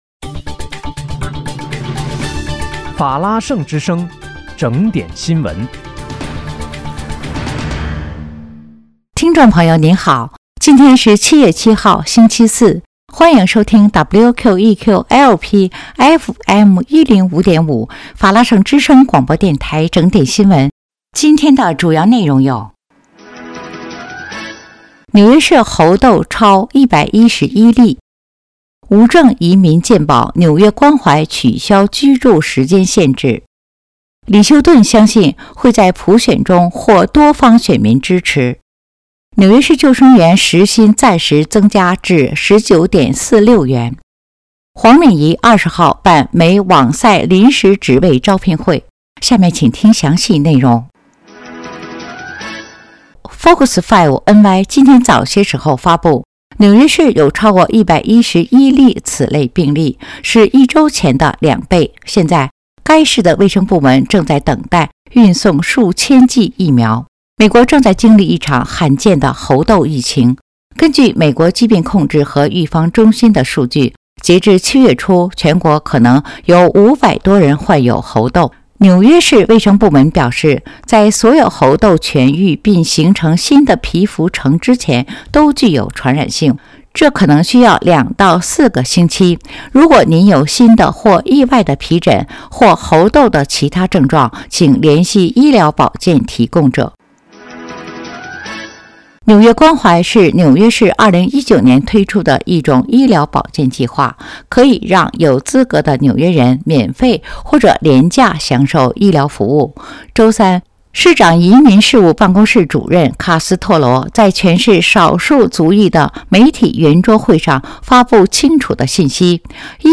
7月7日（星期四）纽约整点新闻